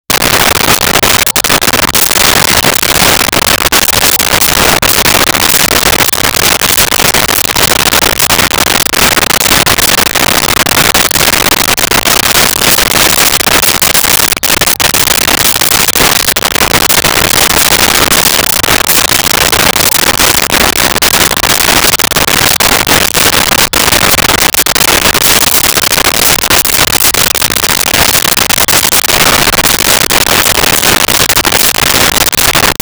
Suburban Neighborhood
Suburban Neighborhood.wav